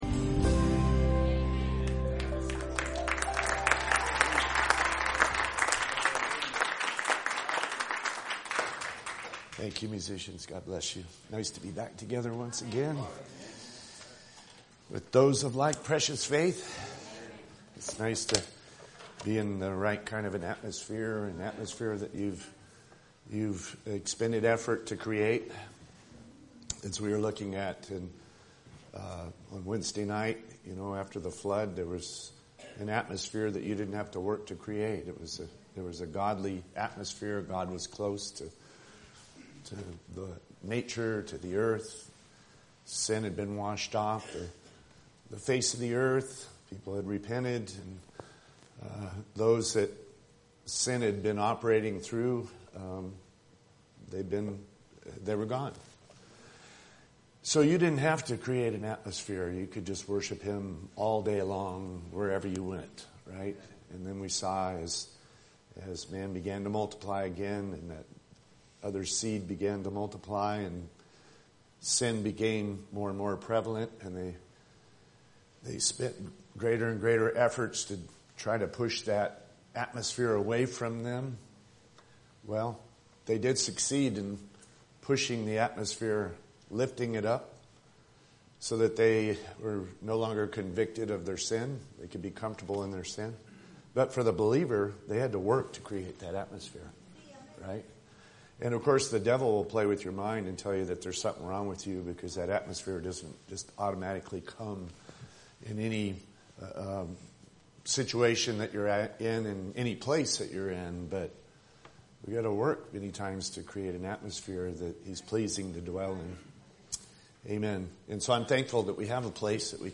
during the song service and at the beginning of the sermon